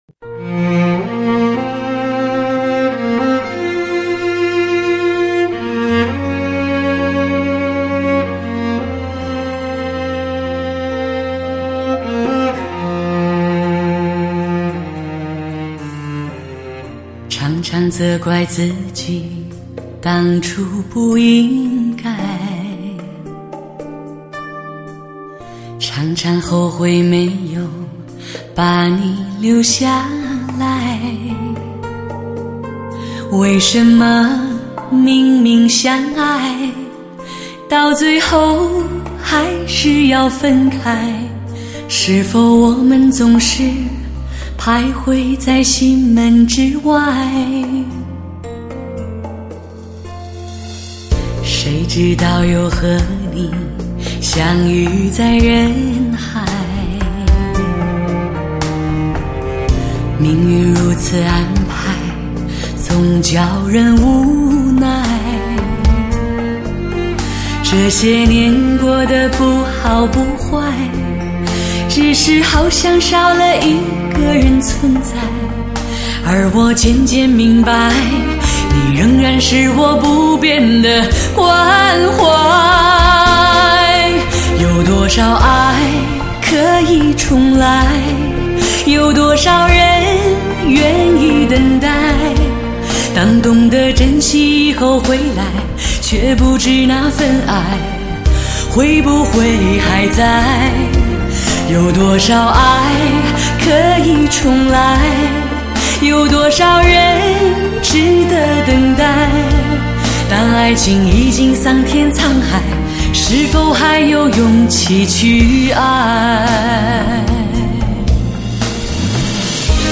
专辑格式：DTS-CD-5.1声道
时而婉约，时而宏大， 声声入耳，段段悠扬，轻吟浅唱时